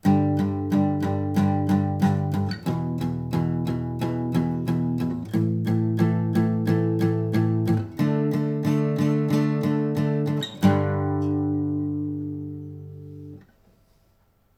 Rütmiharjutused (strum patterns)
8. Tüüpjärgnevus I-vi-ii-V, sama, mis nr 4 ja 5, ainult kvintidega: